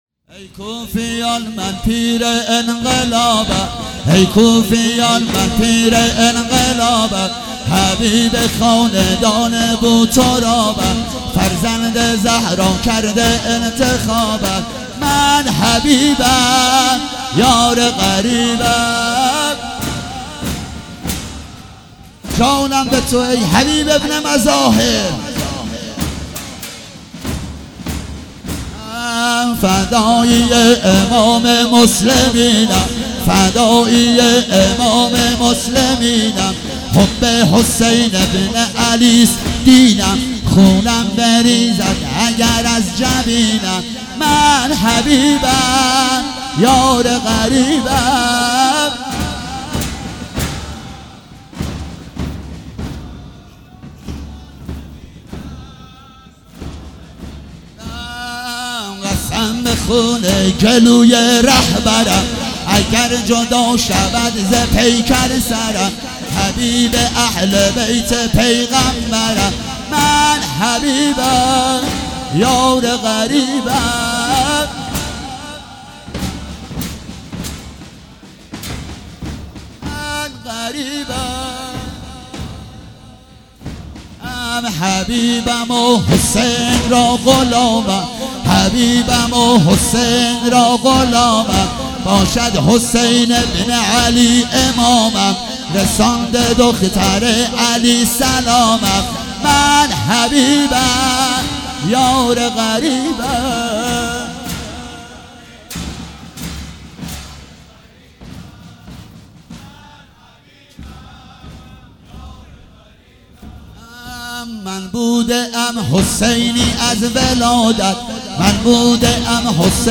شب پنجم محرم96 - زنجیرزنی